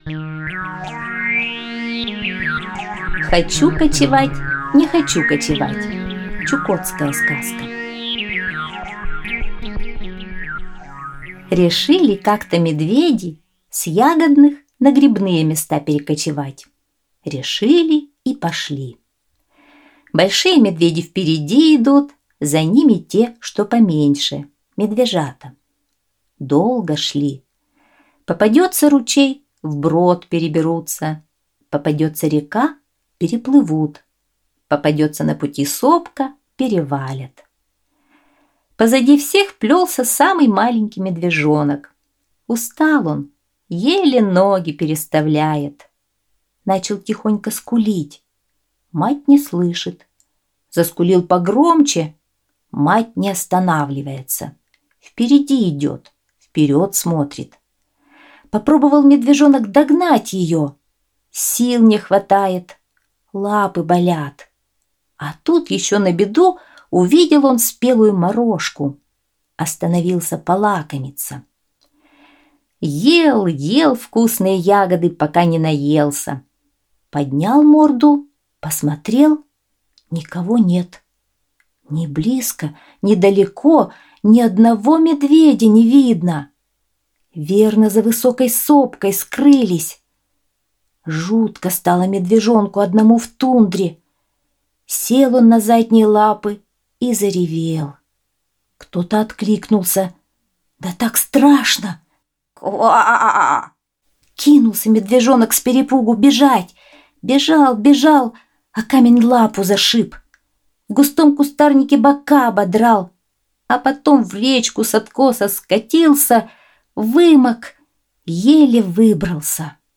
Чукотская аудиосказка